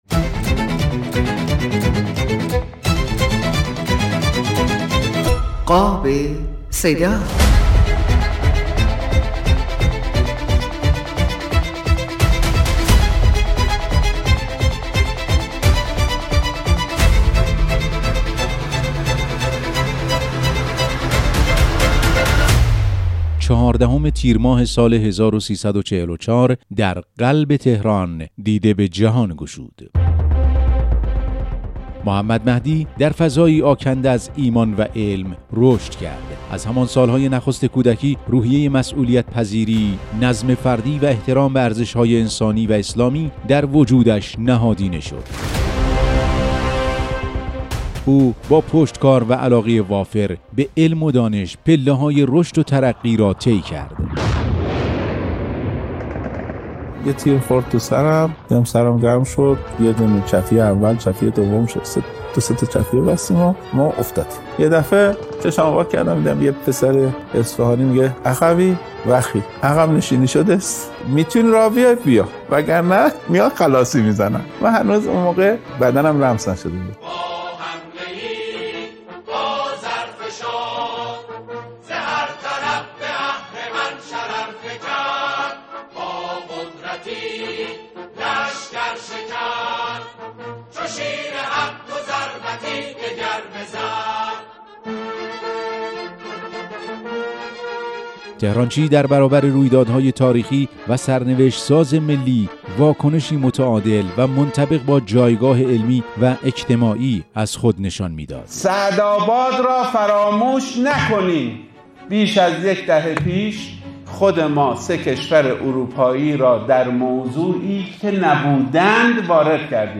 در این برنامه، با استفاده از صدای برخی گفت و گوهای شهید طهرانچی، حوادث مختلف زندگی این دانشمند شهید و موضع گیری هایش از جمله جانباز شدن در دفاع مقدس، روند مذاکرات بر سر مسأله هسته ای و چالش های علمی کشور را بررسی کرده است.